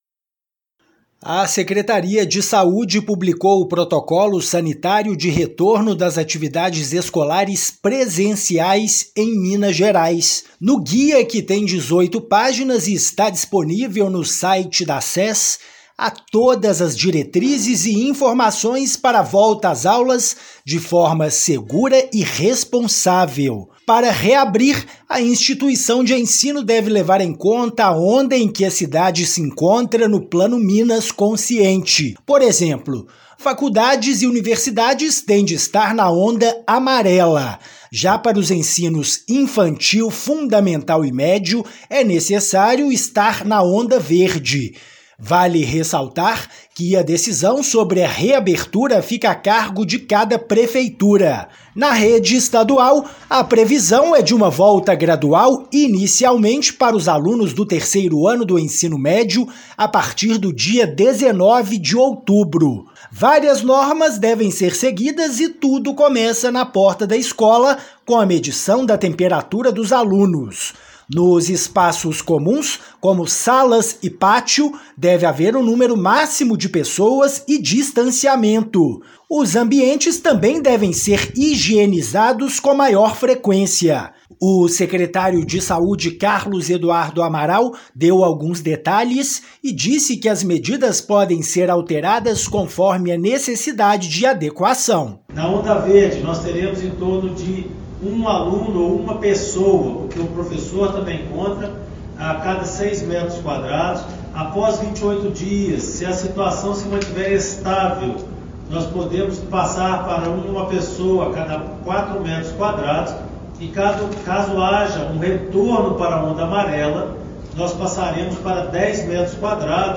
Documento traz, entre outras definições, os critérios mínimos para retomada das atividades escolares. Ouça a matéria de rádio.